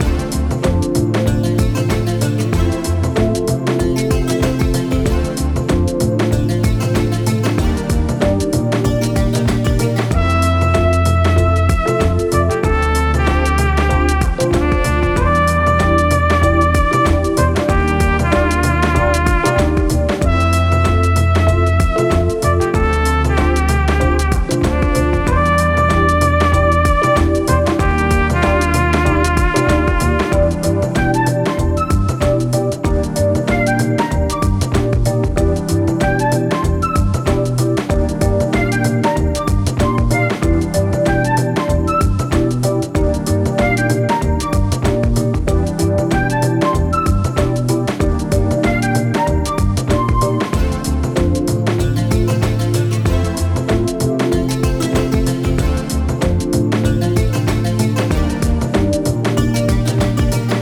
Title music for puzzle game.